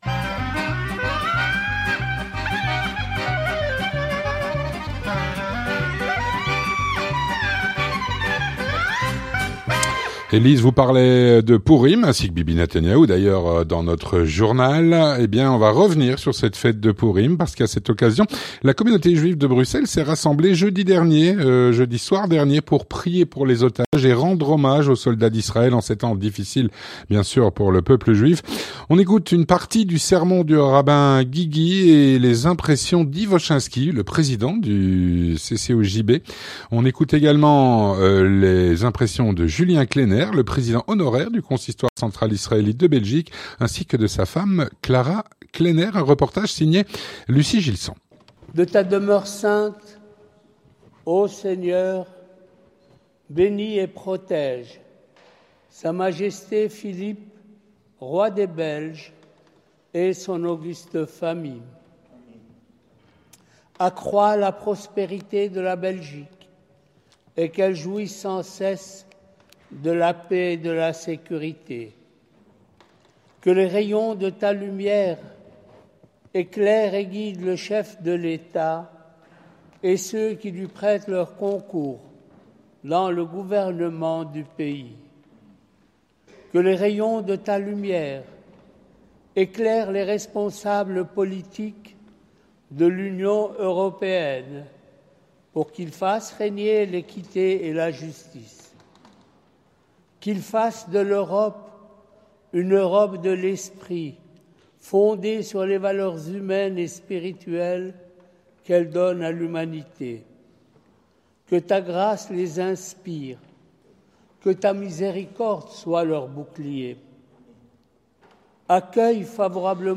L'interview communautaire - A l’occasion de Pourim, la communauté juive de Bruxelles s’est rassemblée jeudi soir, afin de prier pour les otages et rendre hommage aux soldats israéliens.